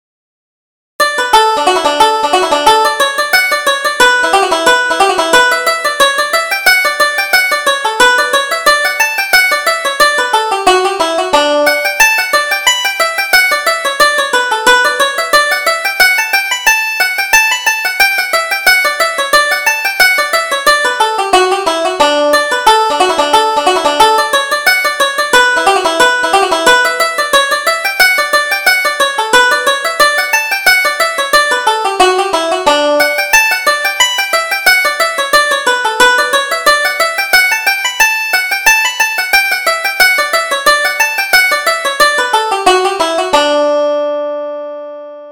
Reel: Salamanca Reel